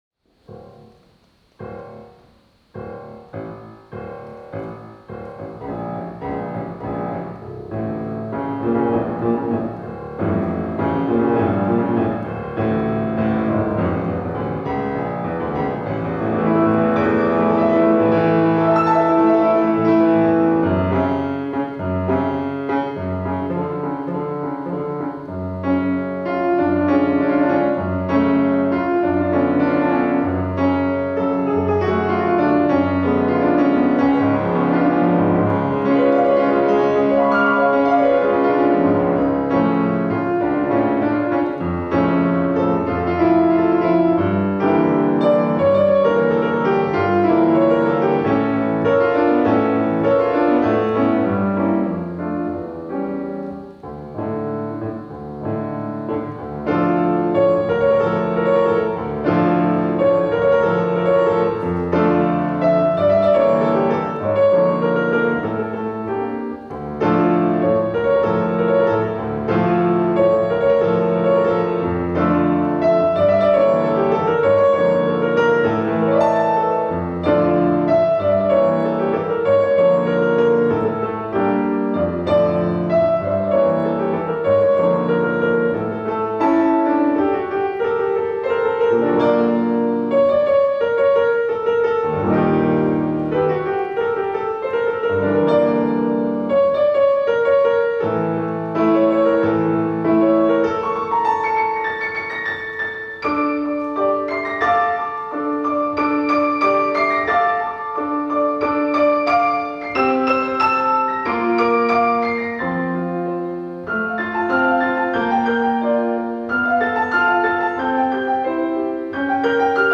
2022-09-19 Jews in the Musical Culture of Galicia/ fortepian
Parafraza na tematy żydowskie Oi Avrom.wav (66.88 MB)
Koncert odbył się w sali koncertowej Swarthout Recital Hall. Zgromadzona licznie publiczność miała możliwość poznać współczesną, polską muzykę wokalno-instrumentalną skomponowaną przez Krzysztofa Kostrzewę i Ewę Nidecką oraz 3 parafrazy na temat żydowskich melodii ludowych Juliusza Wolfsohna na fortepian solo.